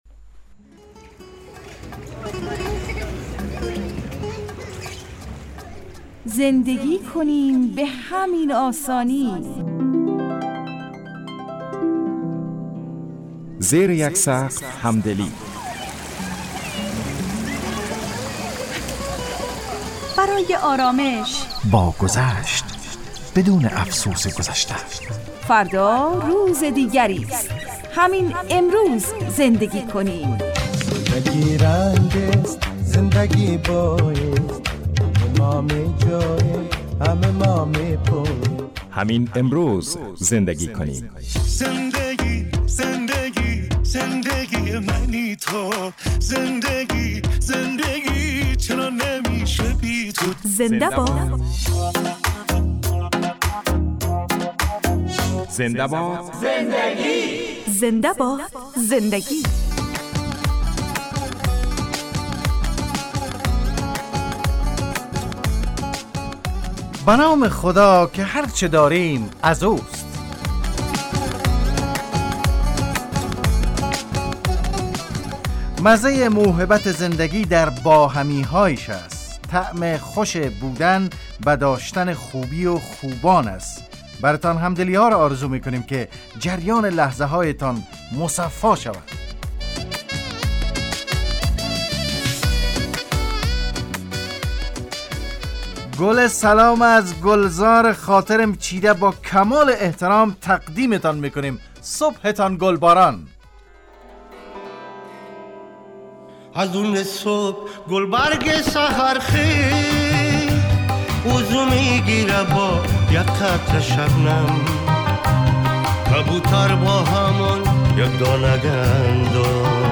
برنامه خانوادگی رادیو دری